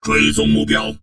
文件 文件历史 文件用途 全域文件用途 Enjo_skill_05_1.ogg （Ogg Vorbis声音文件，长度1.0秒，124 kbps，文件大小：16 KB） 源地址:地下城与勇士游戏语音 文件历史 点击某个日期/时间查看对应时刻的文件。